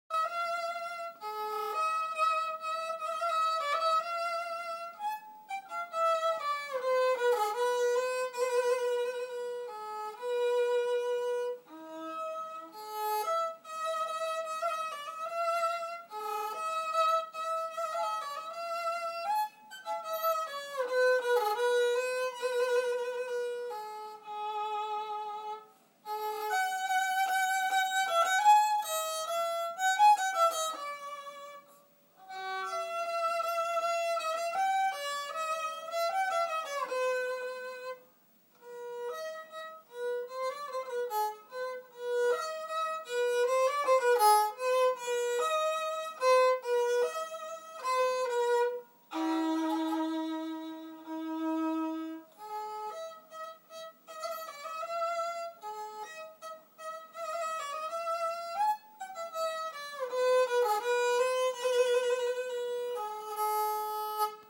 last shot @ 3Dprinted violin